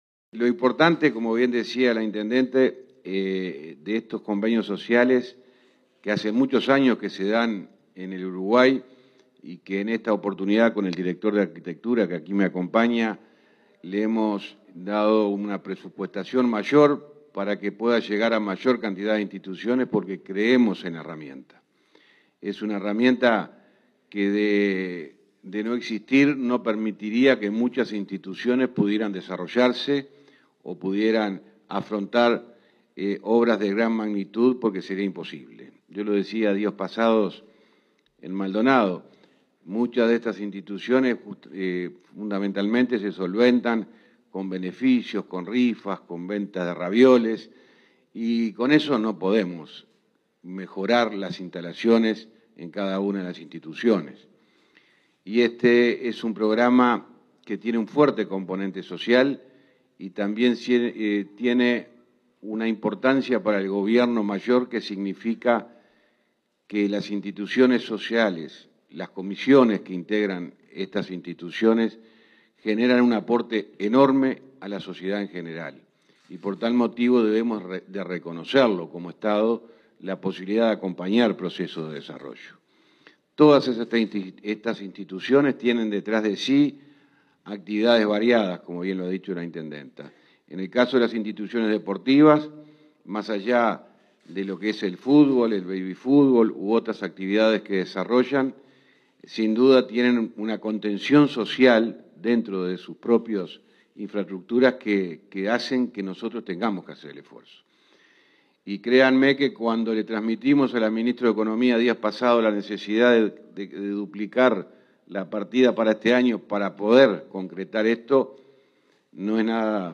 Palabras del ministro del MTOP, José Luis Falero